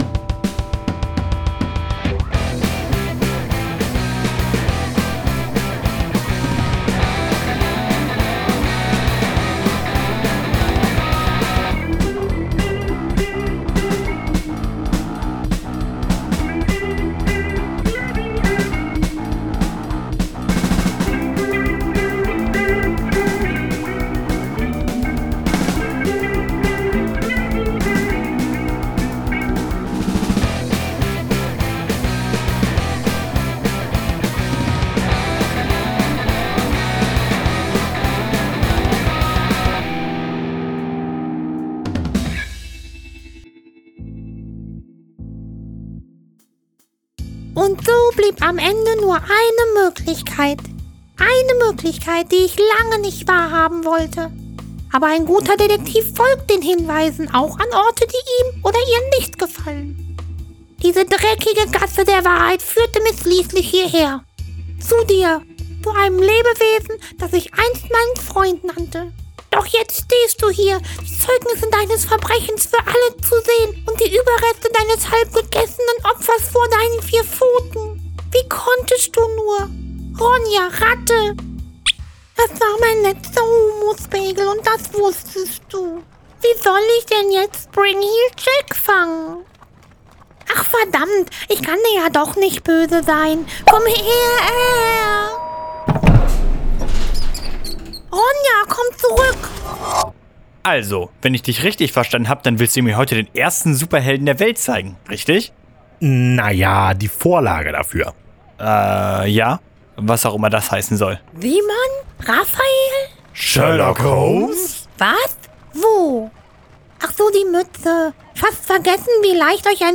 Creature Feature - Ein Hörspiel